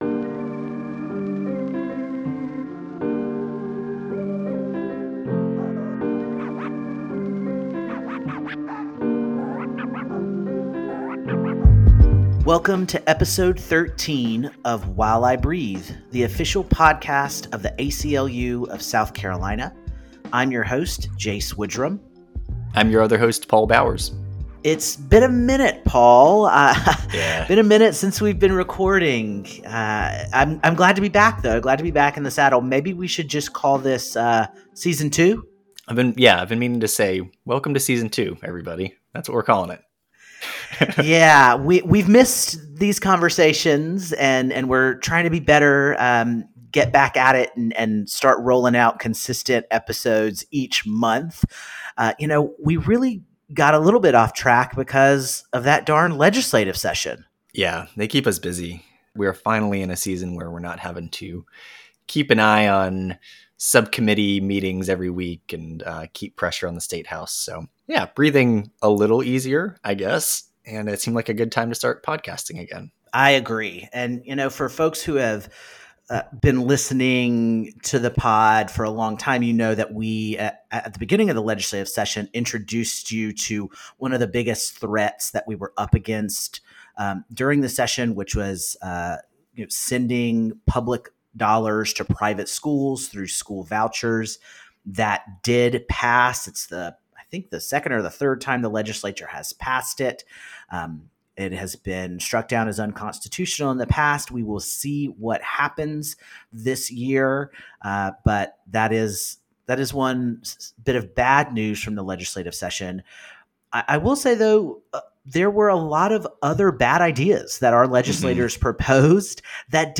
For the Season 2 premiere, we’re hosting a panel conversation with transgender South Carolinians from a broad range of backgrounds and experiences.